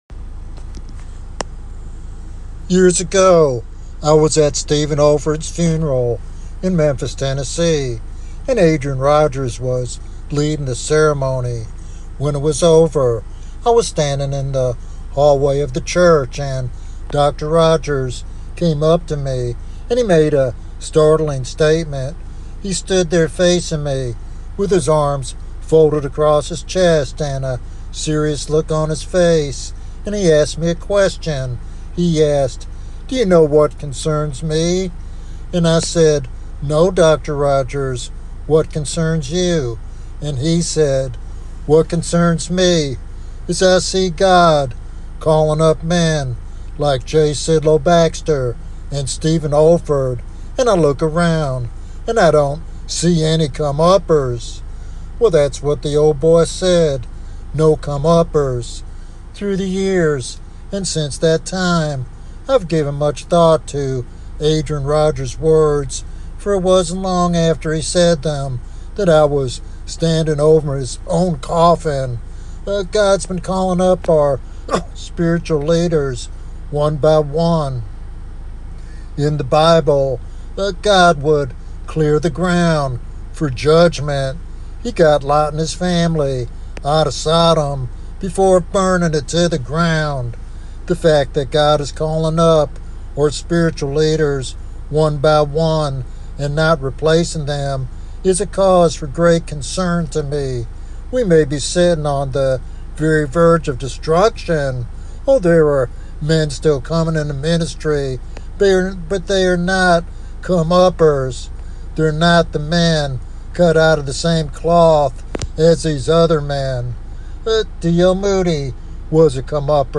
What does 'comeupper' mean in this sermon?